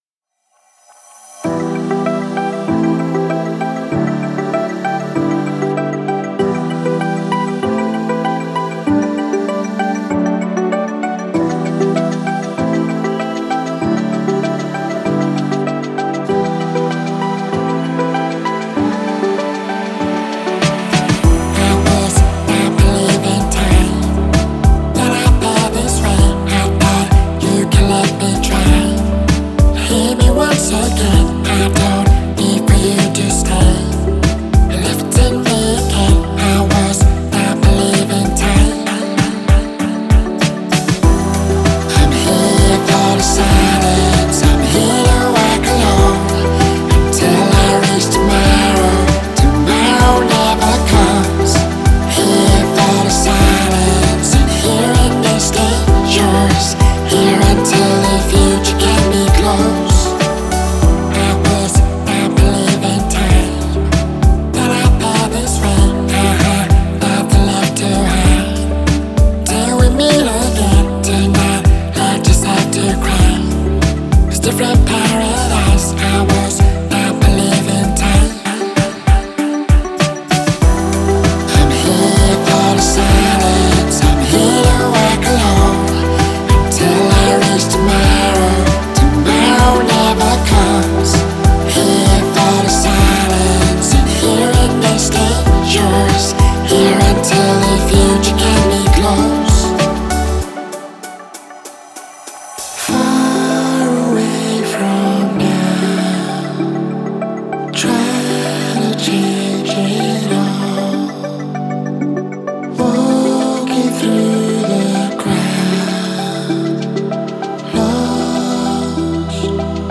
это трек в жанре электронной поп-музыки